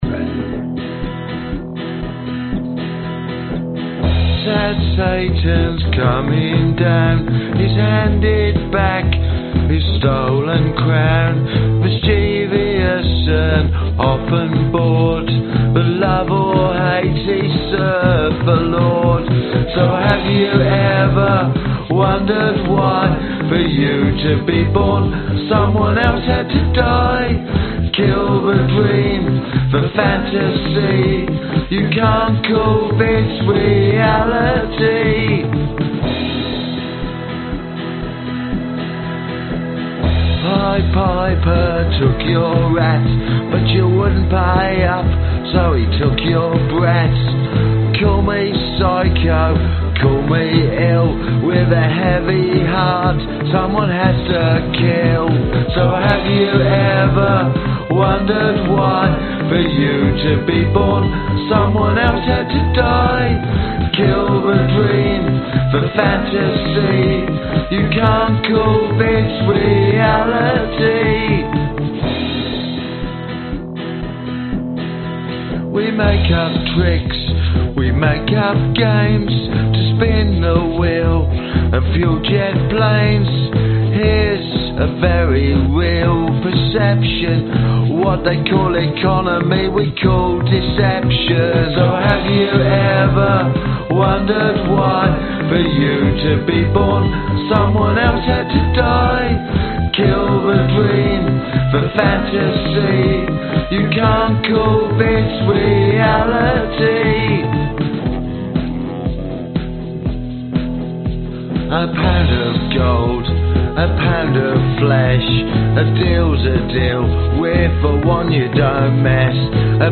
Tag: 男声 电子 吉他 贝司 合成器 循环